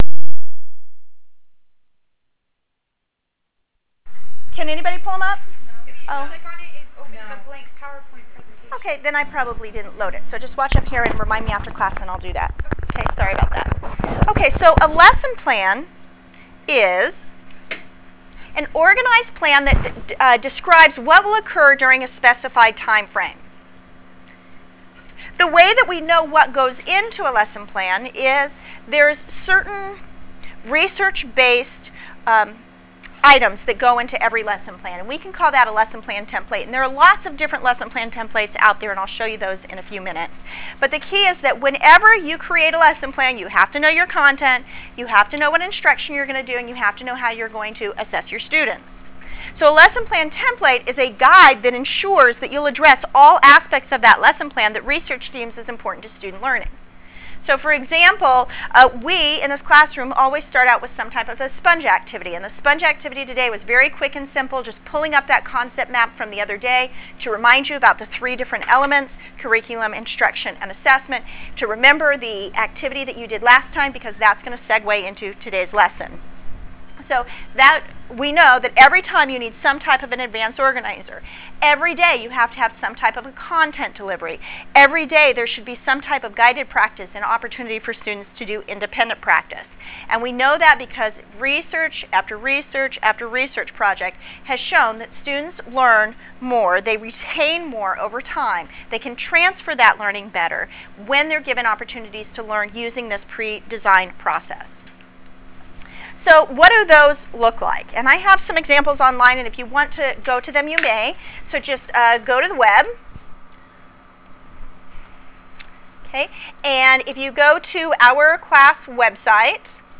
Lecture_LessonPlaning9_13_06.wav